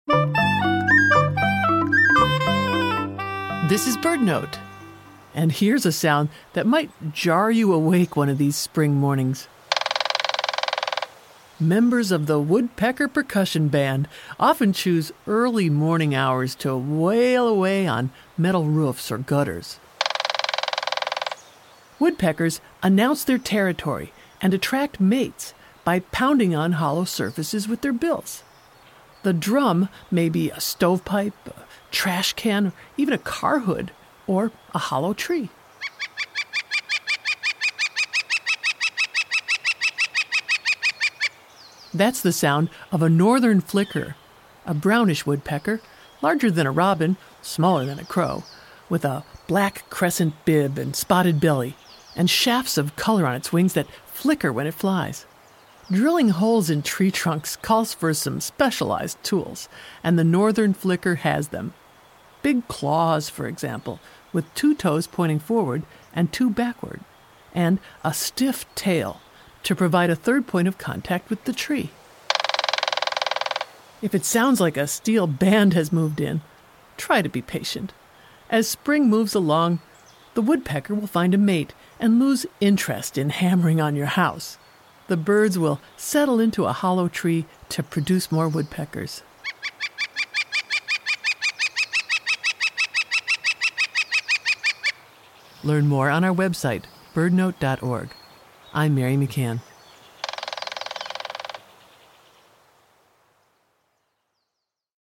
Springtime brings the sound of a woodpecker, like the Northern Flicker, drumming on a hollow surface. Members of the woodpecker percussion band announce their territory and attract mates, as they pound away on metal roofs or gutters. Drilling holes in tree trunks calls for some specialized tools, and the North Flicker has them: big claws, two toes pointing forward and two backward, and a stiff tail to prop itself up.